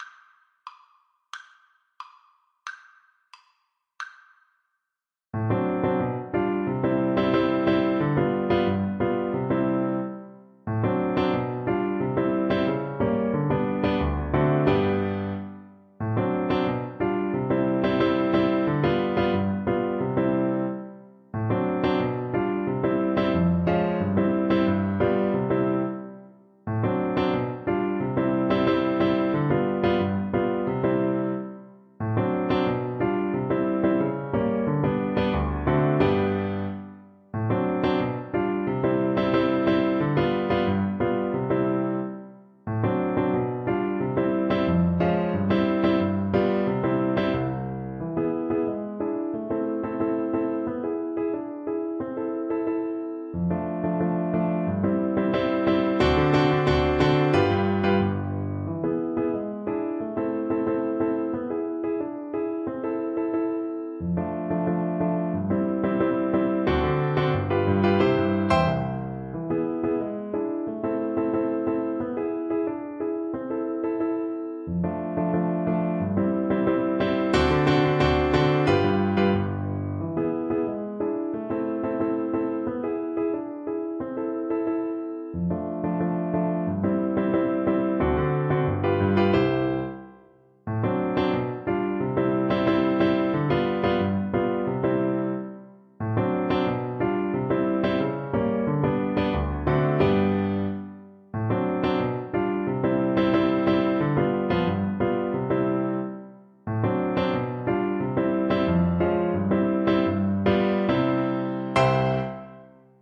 Flute
C major (Sounding Pitch) (View more C major Music for Flute )
2/4 (View more 2/4 Music)
Classical (View more Classical Flute Music)
Polkas for Flute
BombomFL_kar1.mp3